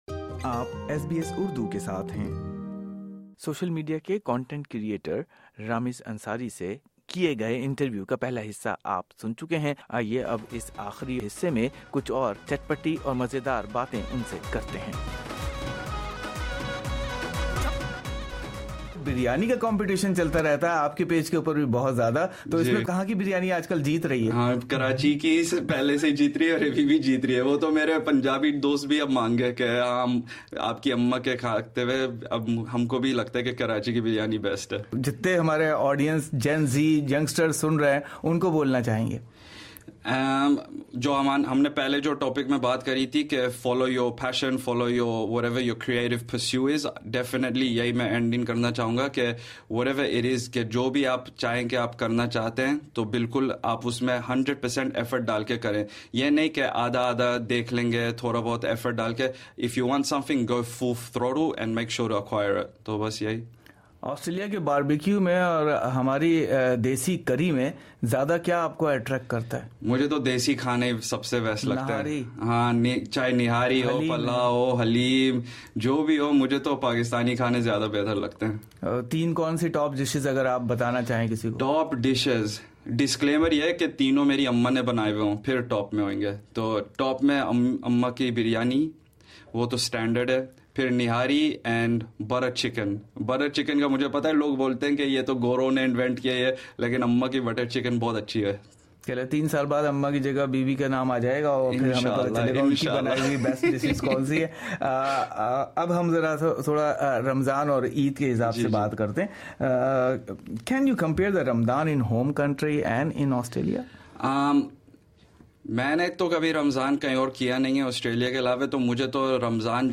ان کے ساتھ کی گئی دلچسپ گفتگو میں کرکٹ، شادی، اور روایتی ملازمت کے ساتھ ساتھ سوشل میڈیا کو روزگار کا ذریعہ بنانے جیسے موضوعات شامل ہیں۔اس دلچسپ پوڈ کاسٹ میں بریانی لاہور بمقابلہ کراچی، سڈنی یا میلبورن میں بہتر کون سا شہر، اور ان کی شادی کا بھی تذکرہ رہا۔